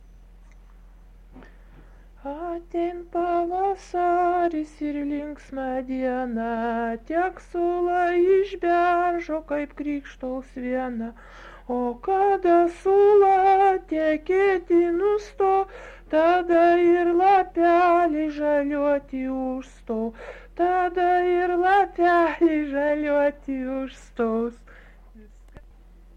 Dalykas, tema daina
Atlikimo pubūdis vokalinis
Garso defektai pabaigoj